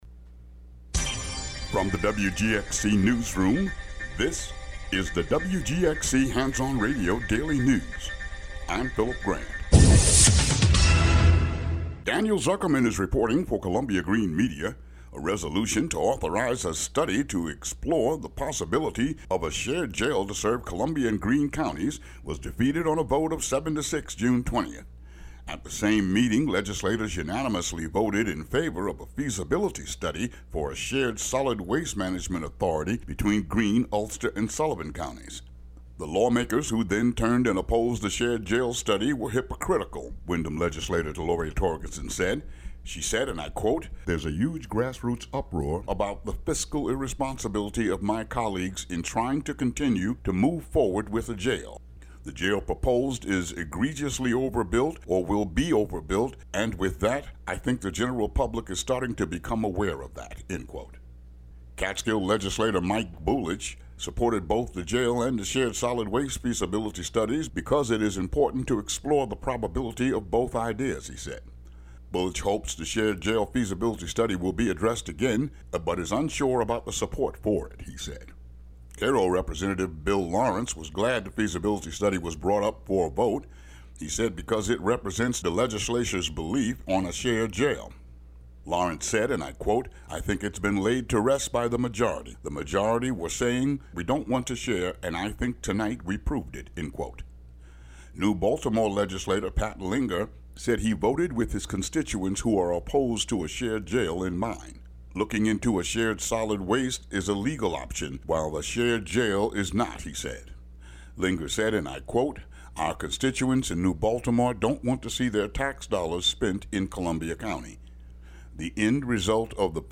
Daily headlines for WGXC.